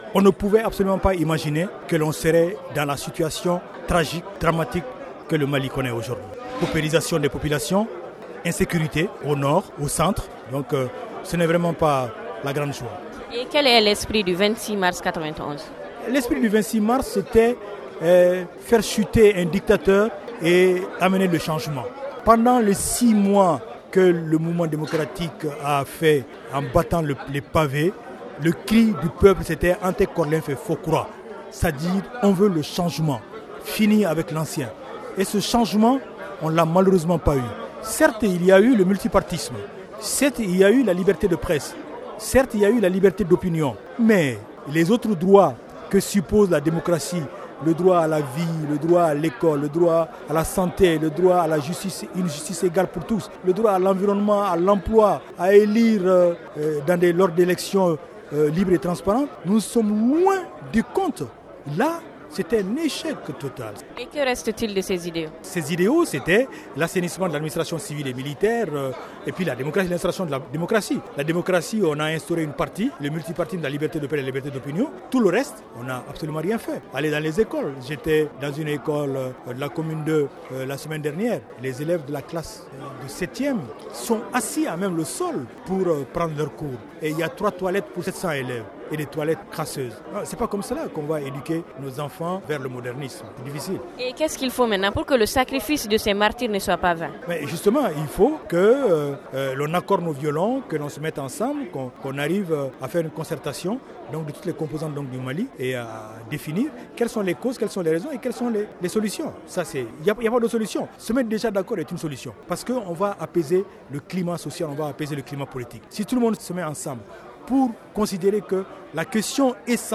Le conférencier Cheik Oumar Sissoko cinéaste et ancien ministre de la culture est au micro